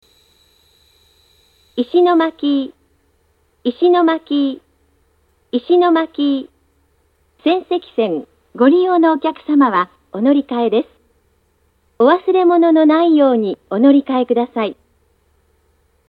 到着放送